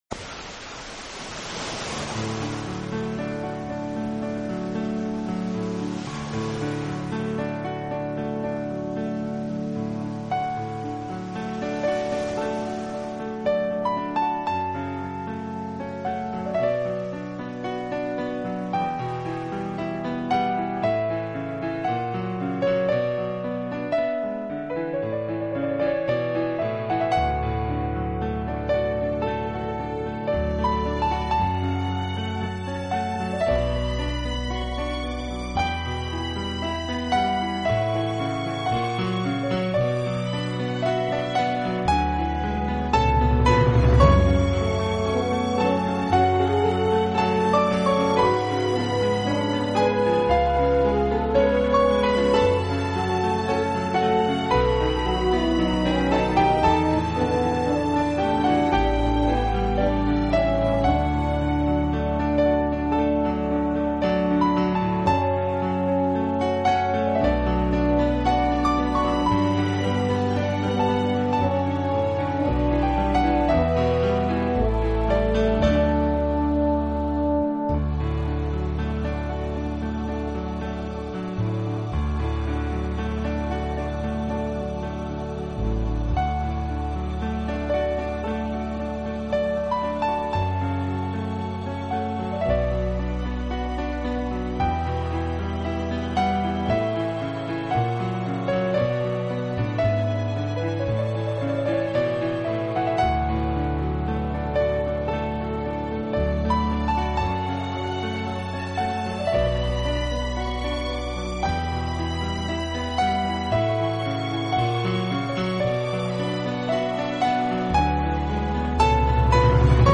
在优雅的音乐中，在清新的大自然声响里，